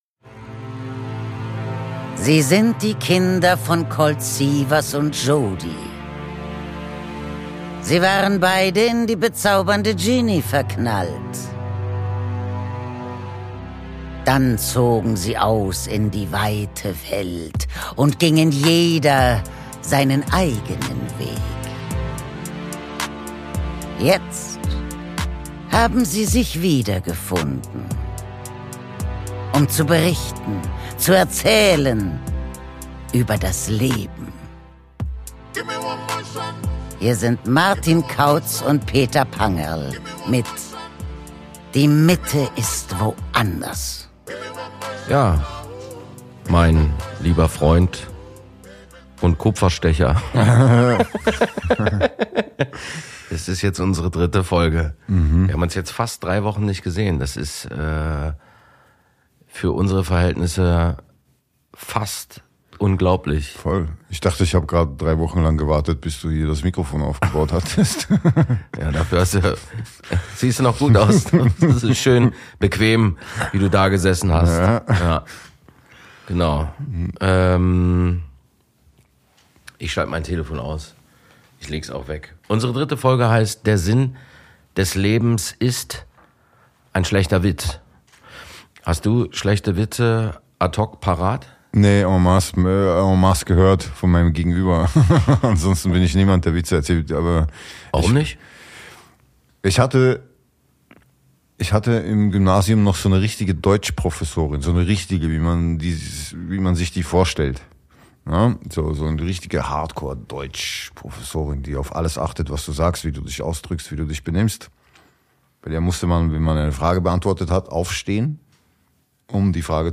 Es geht um Werte, echten Reichtum und Freundschaft. Aus dem 25h Hotel im Wiener Museumsquartier